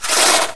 growl2.wav